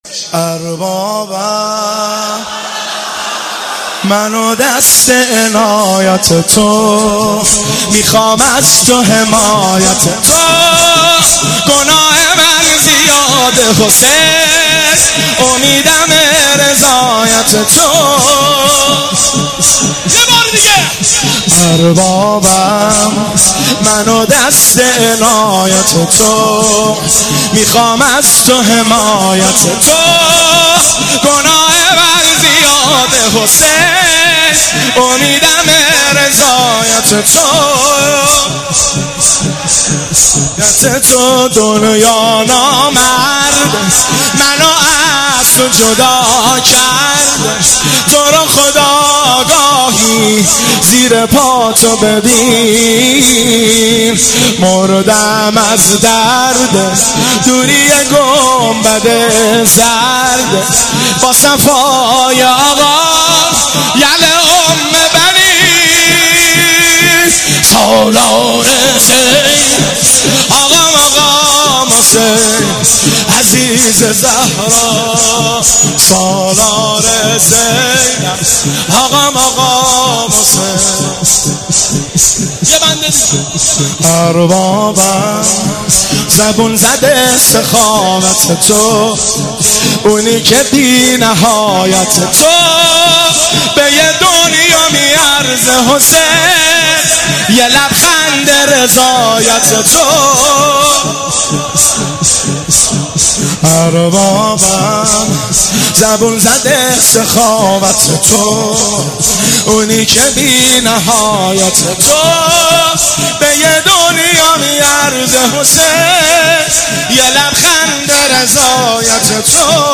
هیئت بین الحرمین طهران شب پنجم محرم97